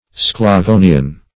Sclavonian \Scla*vo"nian\